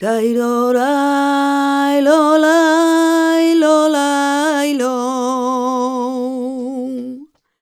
46a03voc-g#m.wav